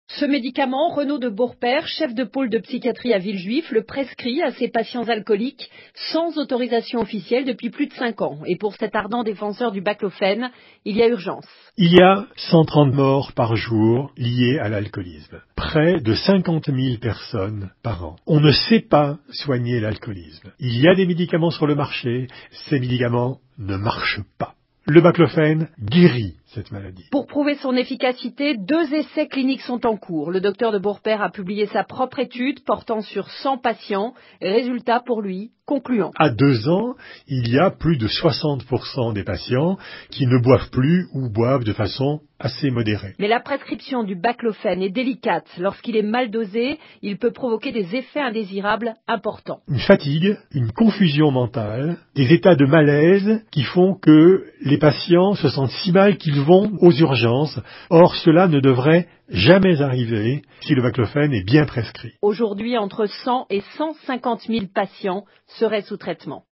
Documents baclofene / Presse/Internet baclofène / Radio
« Le baclofène guérit de l’alcoolisme », dit un médecin